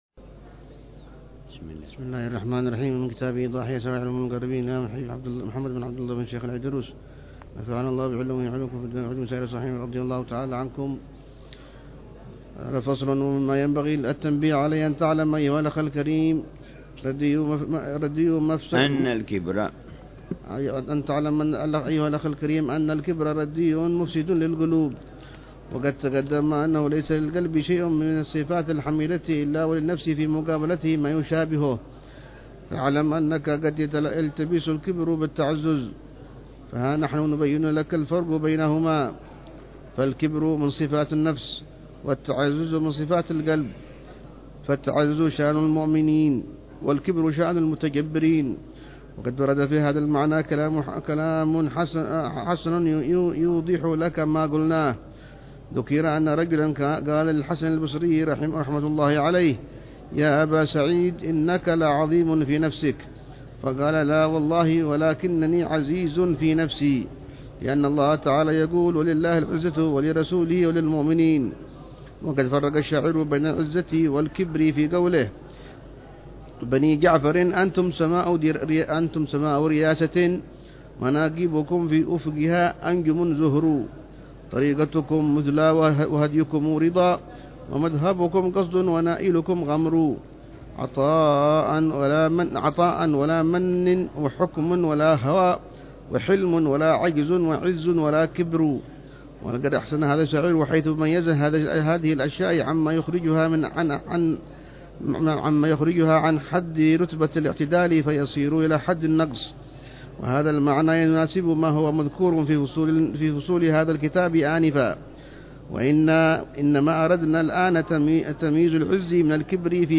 شرح للحبيب عمر بن حفيظ على كتاب إيضاح أسرار علوم المقربين للإمام محمد بن عبد الله بن شيخ العيدروس، الذي اهتم بتوضيح معالم طريق السالكين والعبا